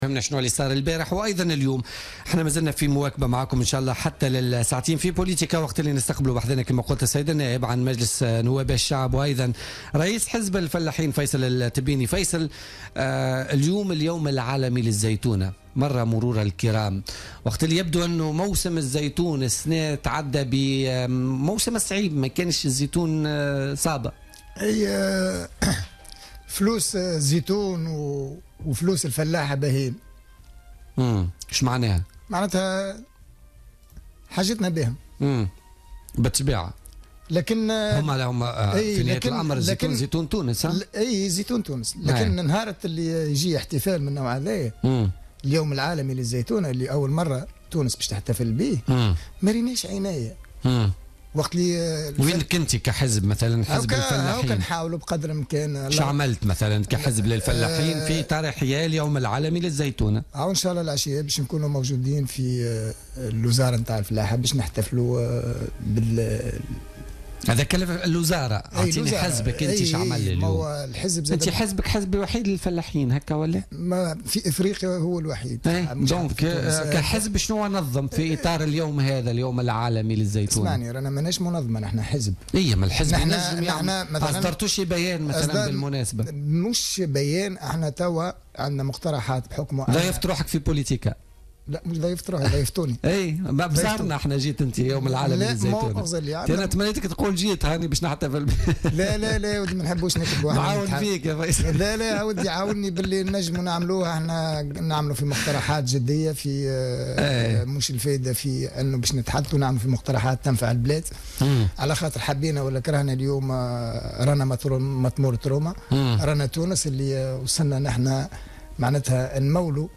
أكد النائب عن حزب الفلاحين في مجلس نواب الشعب فيصل التبيني ضيف بولتيكا اليوم 30 نوفمبر 2016 أن السوق السوداء أضرت كثيرا بالإنتاج المحلي وبالفلاحين في تونس .